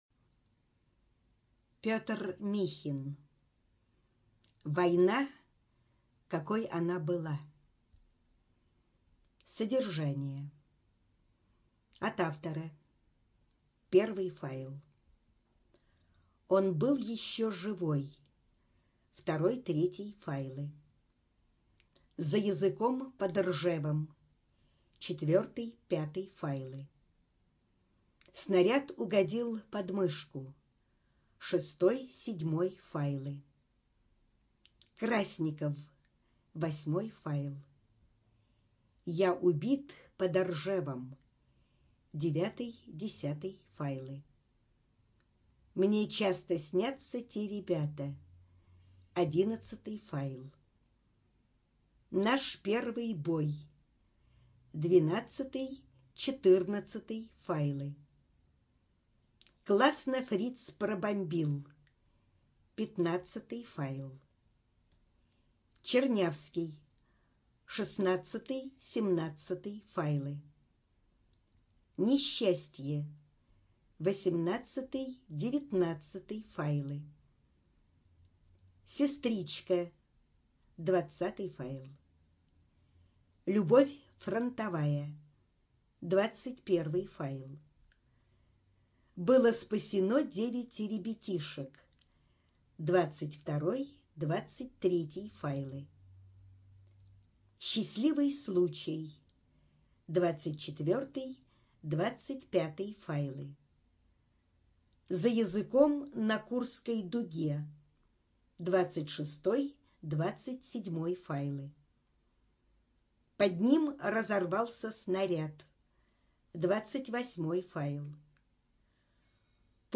Студия звукозаписиКурская областная библиотека для слепых
Звучание книги отличное.